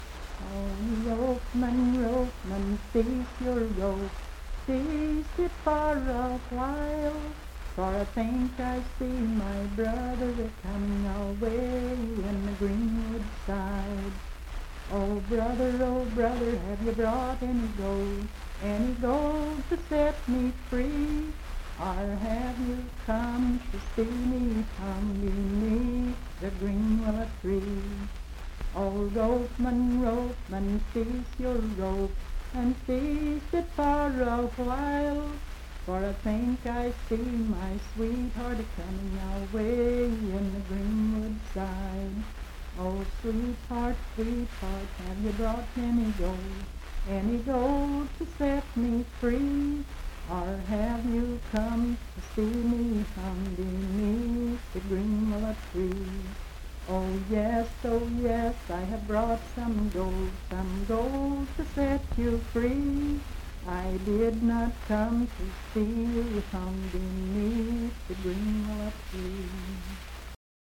Unaccompanied vocal music
Verse-refrain 5(4).
Voice (sung)